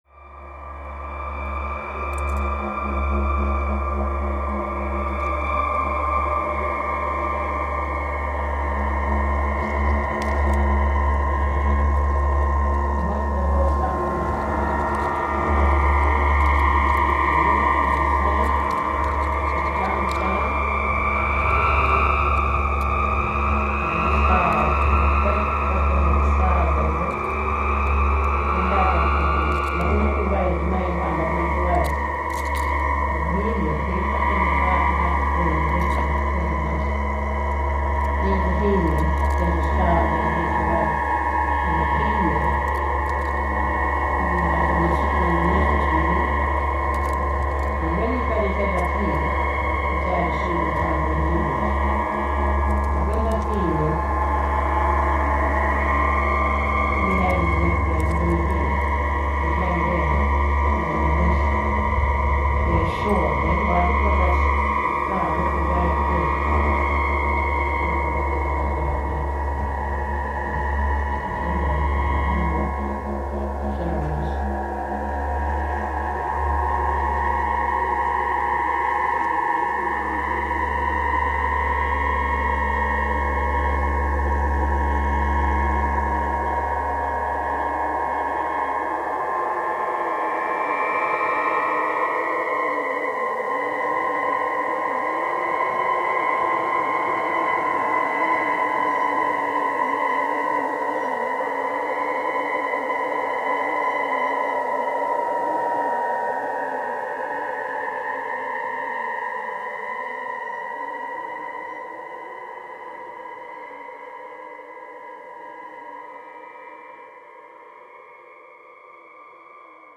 The delicate tones of just intonation, as the harmonics come into audibility as they are played by the wind, are present in many natural phenomena.
They sing a natural polyphony as the overtones build up and retreat.
Aeolian harps on the Mungo Lunette